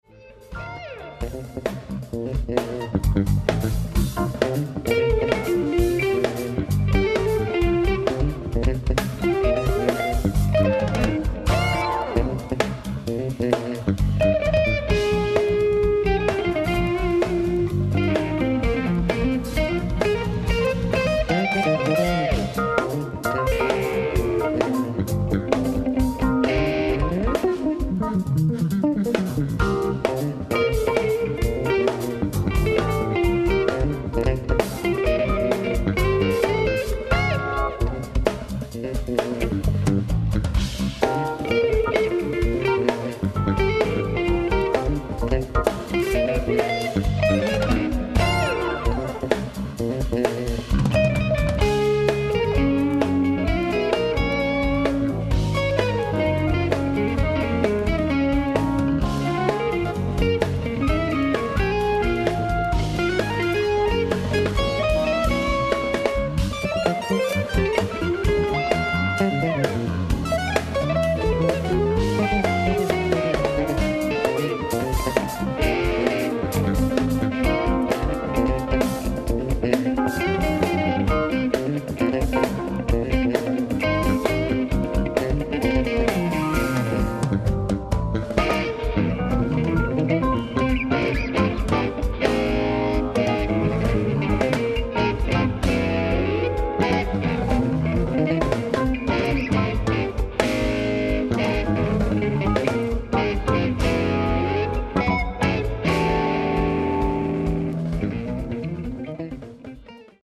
ライブ・アット・アホイ、ロッテルダム、オランダ 07/12/2025
オフィシャル級クオリティー！！
※試聴用に実際より音質を落としています。